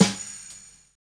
56BRUSHSD2-R.wav